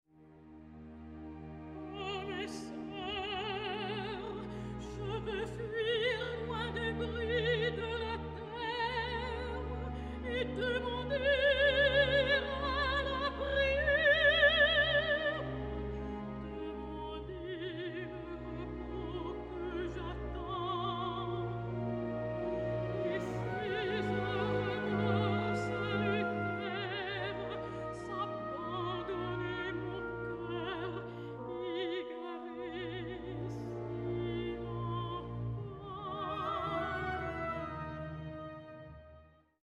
Sacred and Profane Arias